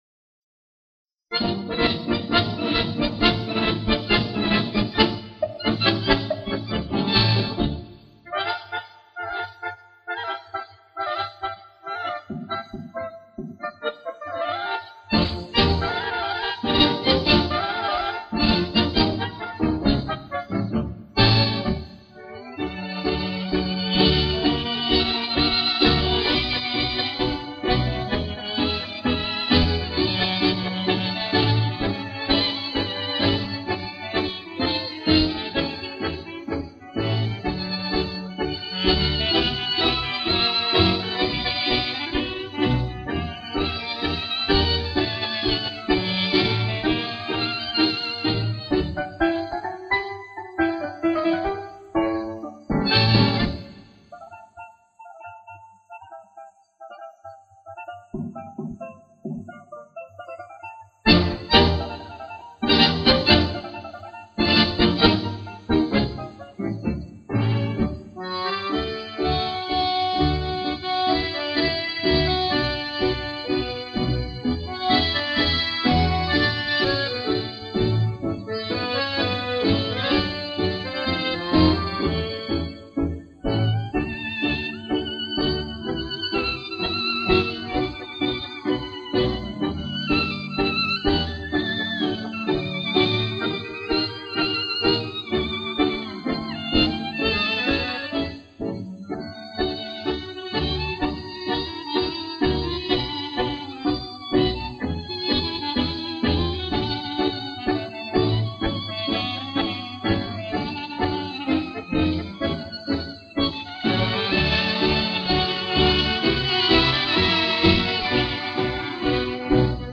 Парочка мелодий с пластинки 1961 года.